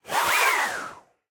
Minecraft Version Minecraft Version latest Latest Release | Latest Snapshot latest / assets / minecraft / sounds / mob / breeze / idle_air3.ogg Compare With Compare With Latest Release | Latest Snapshot
idle_air3.ogg